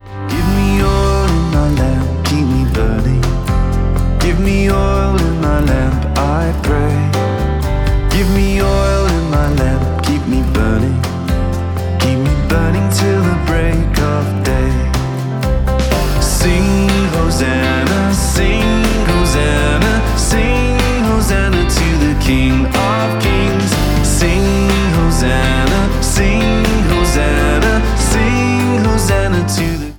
Traditional